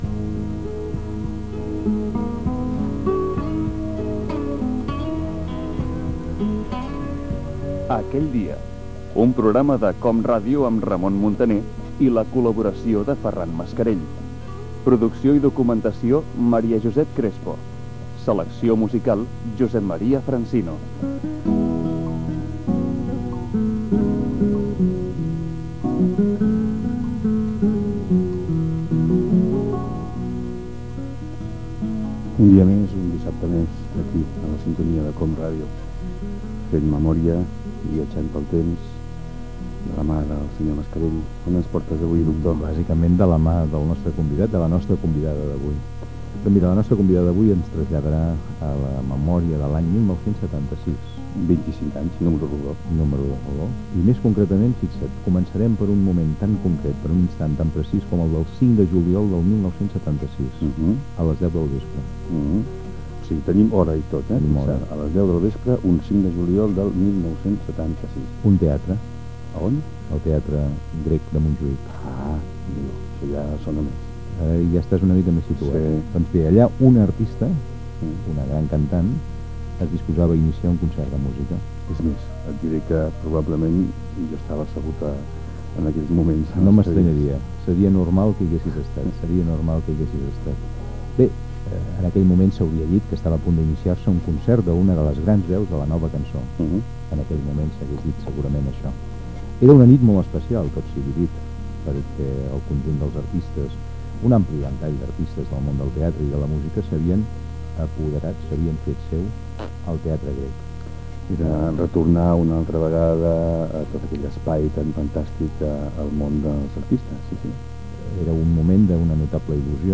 Careta del programa, espai amb la participació de la cantant Maria del Mar Bonet per recordar el 5 de juliol de 1976 quan va actuar al Teatre Grec de Barcelona
Entreteniment